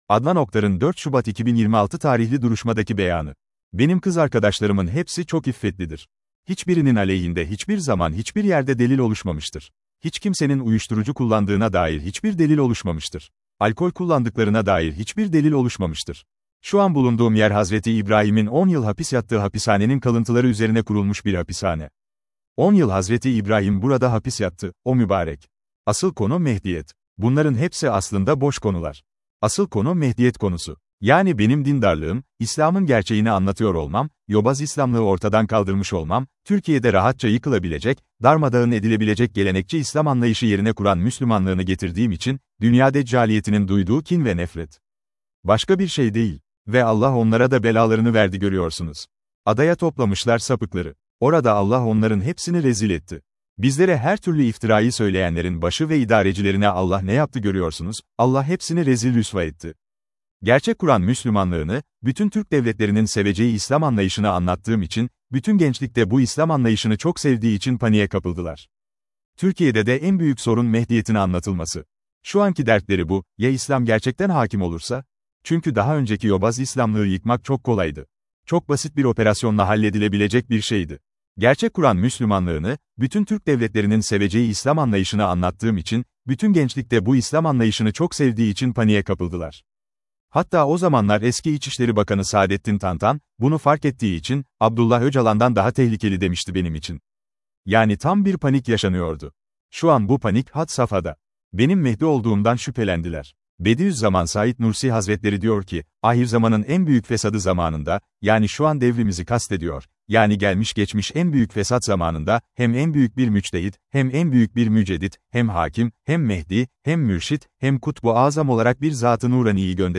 Adnan Oktar’ın 04.02.2026 Tarihli Duruşmadaki Beyanı